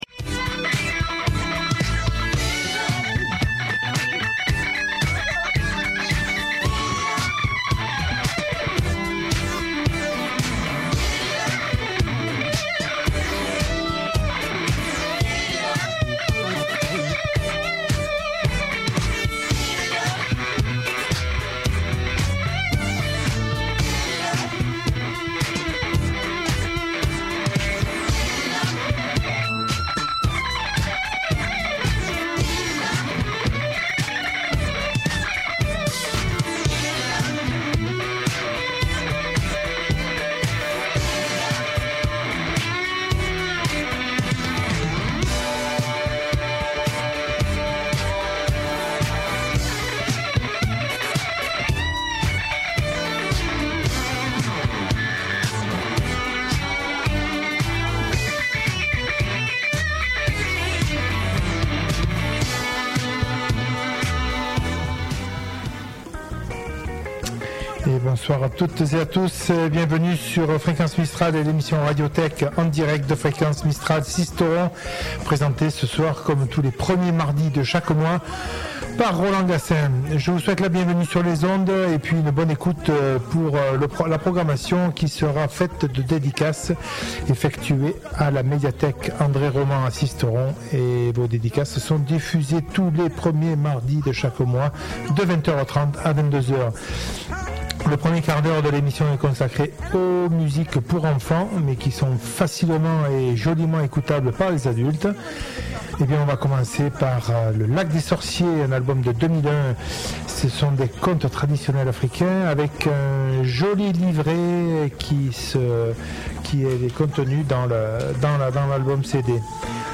Le tout agrémenté par de la chanson pour enfants en début de soirée, du jazz, du blues, de la bonne chanson française et du classique pour terminer l'émission en beauté.